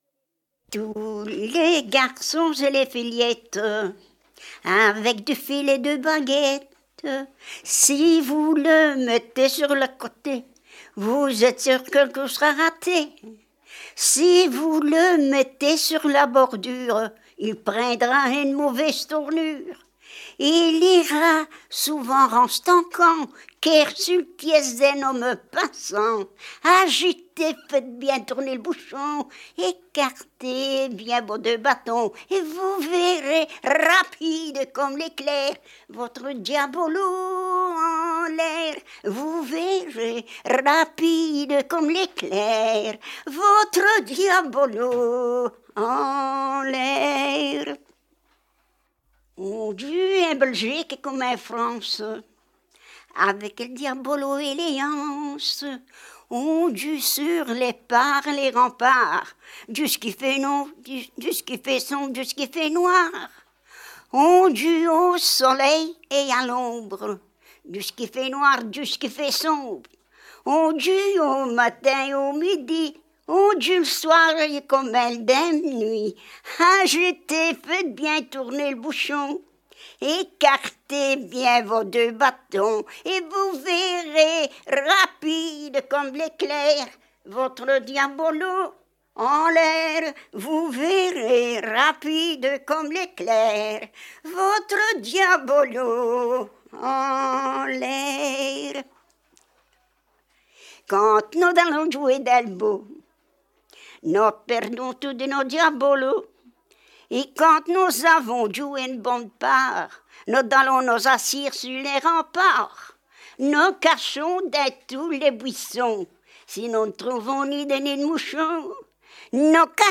Genre : chant
Type : chanson narrative ou de divertissement
Province d'origine : Hainaut
Lieu d'enregistrement : Vierves-sur-Viroin
Support : bande magnétique